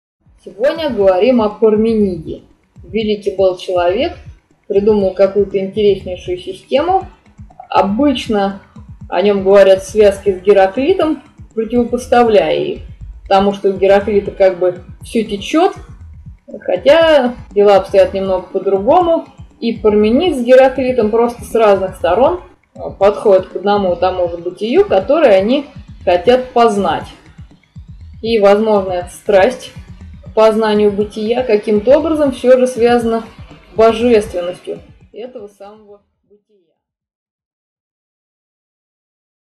Аудиокнига 8. Древнегреческие философы. Парменид | Библиотека аудиокниг